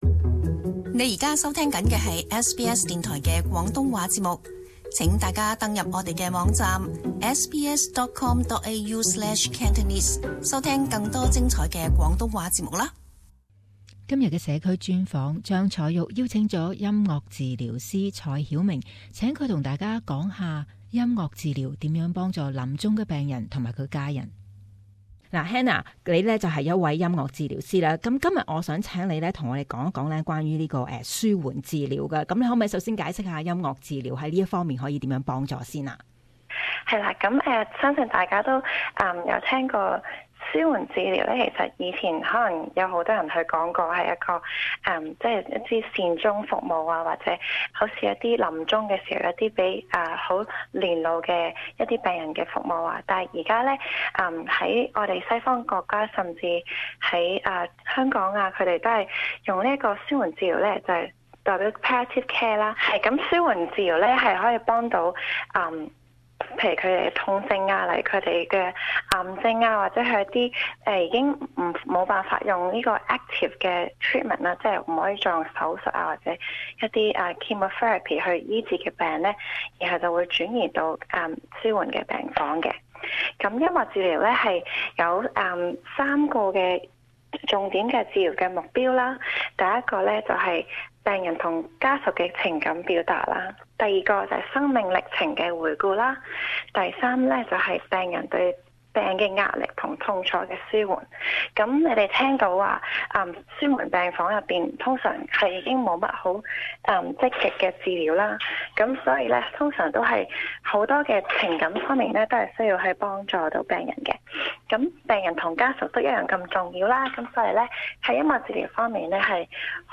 【社區專訪】舒緩關懷治療